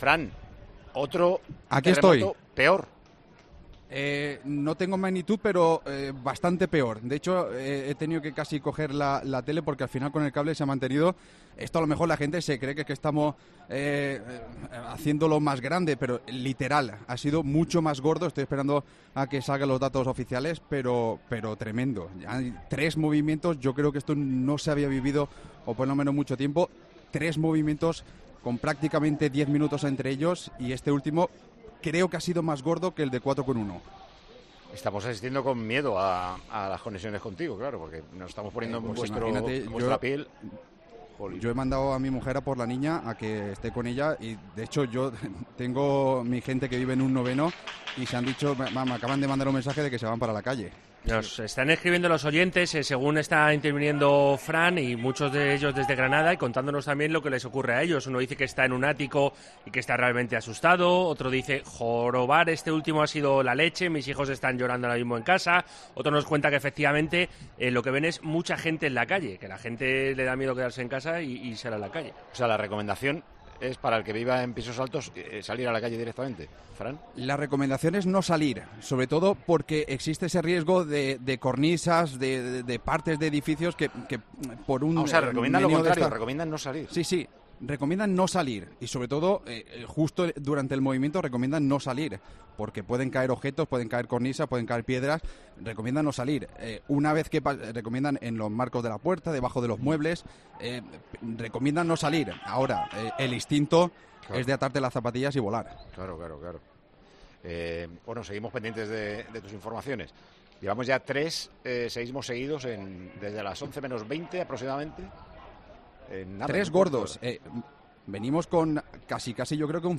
Así ha contado 'Tiempo de Juego' en directo uno de los terremotos que ha vuelto a sacudir Granada